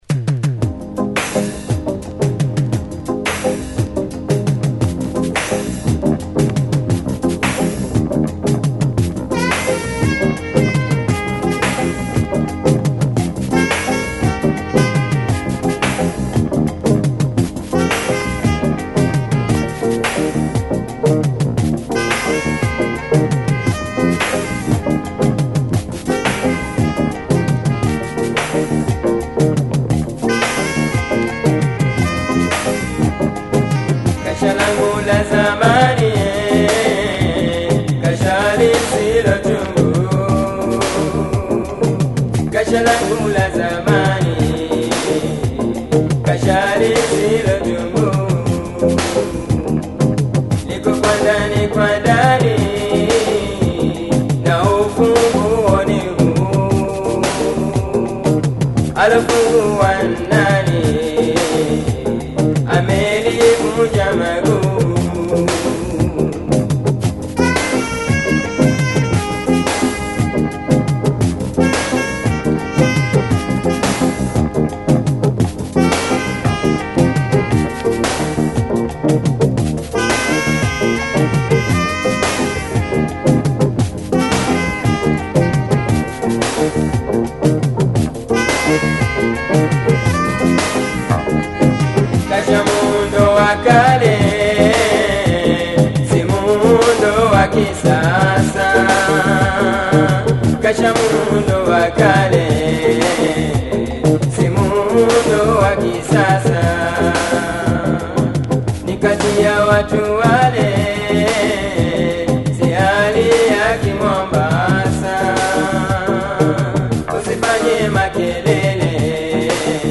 balad